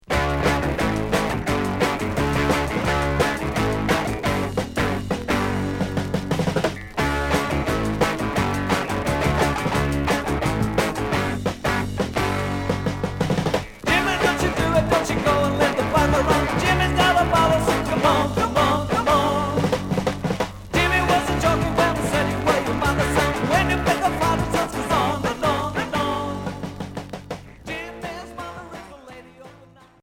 Rock punk